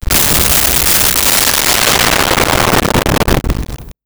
Explosion Distant Large 01
Explosion Distant Large 01.wav